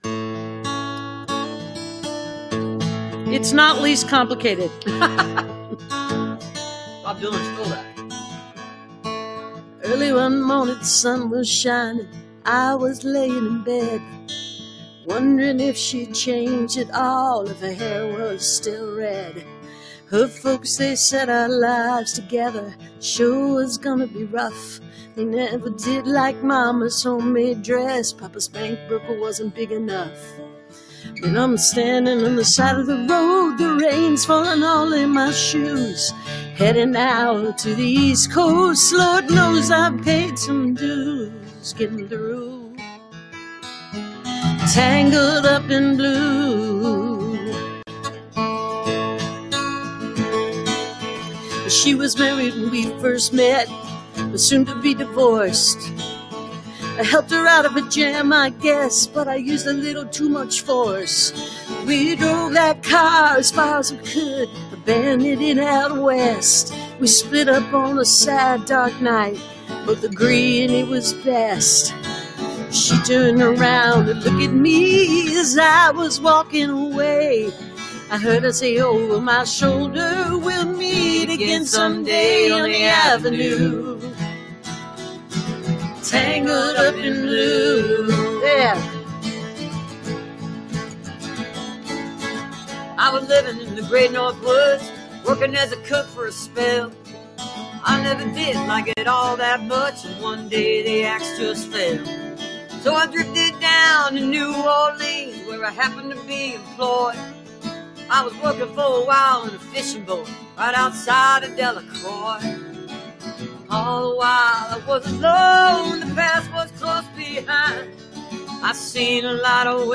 (captured from the youtube video stream)